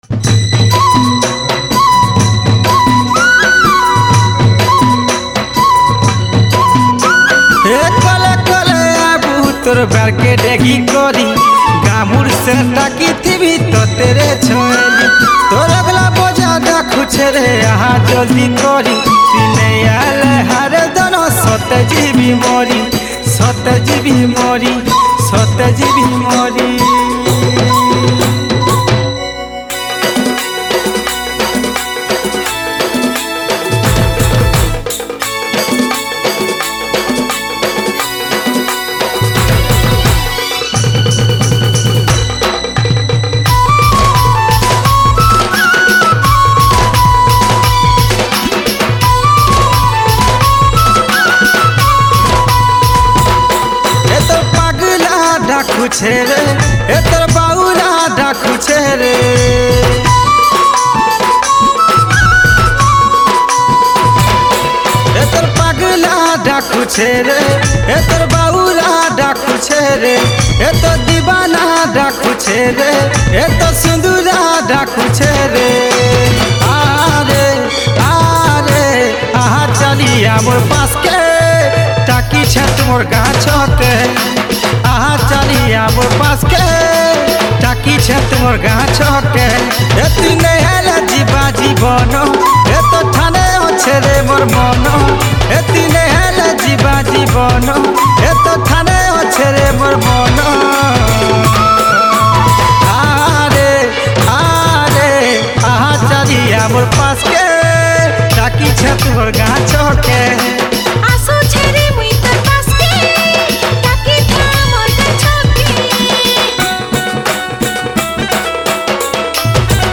Sambapuri Single Song 2022 Songs Download